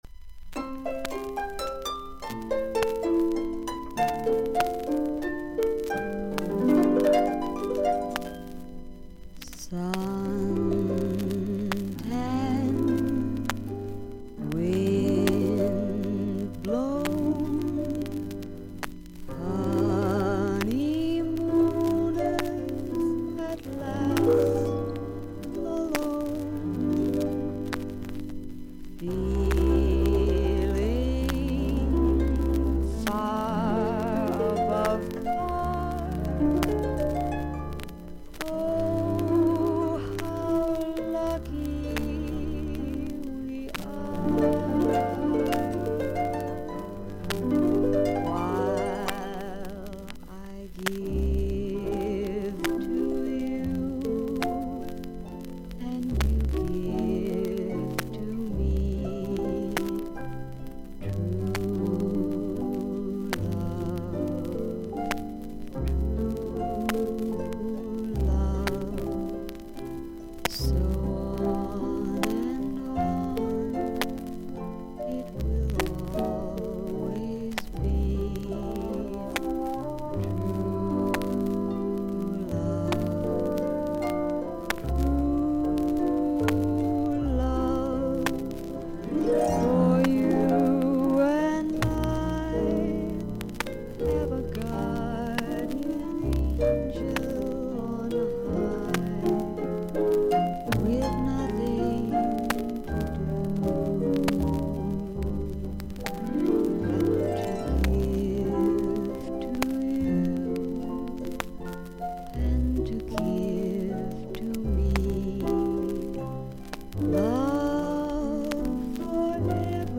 B1最初に3本9mmの深いキズ、2分10秒まで大きなパチノイズあり。
ほかはVG+:少々軽いパチノイズの箇所あり。少々サーフィス・ノイズあり。クリアな音です。